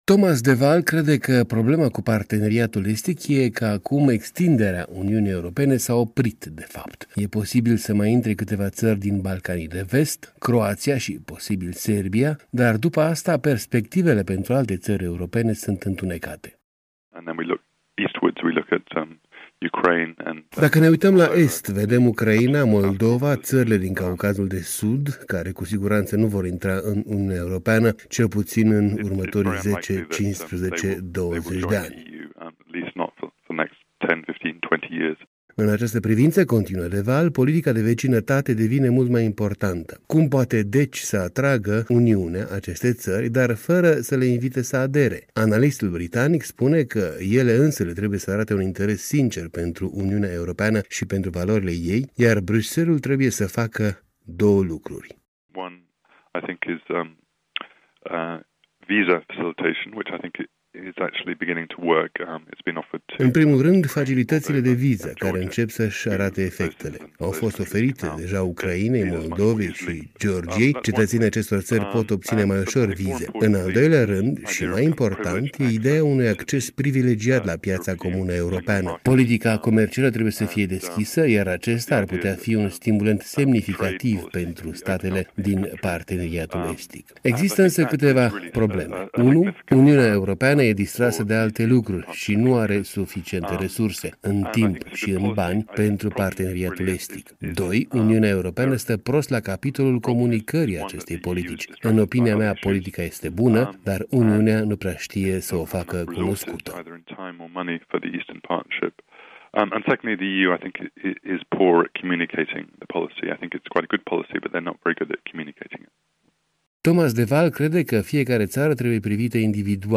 Un interviu cu Thomas de Waal, expert la Centrul de cercetări în politica externă Carnegie Endowment de la Washington.